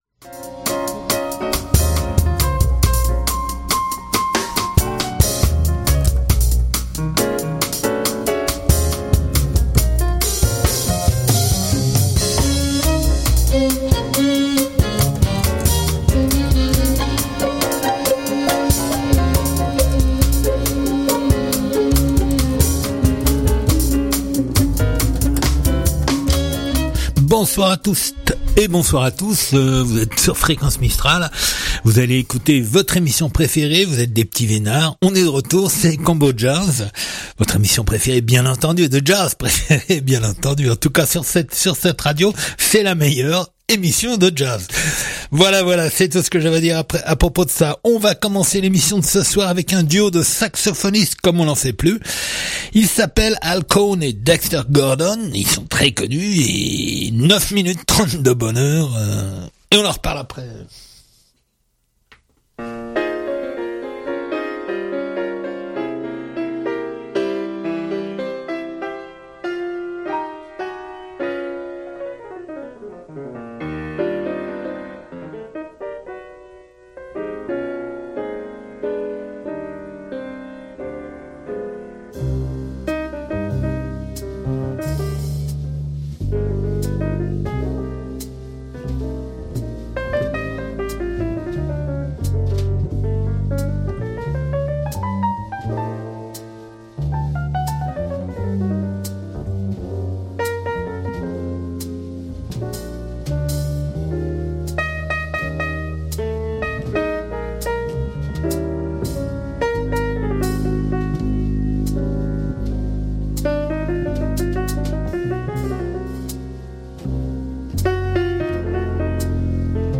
Du jazz rien que du jazz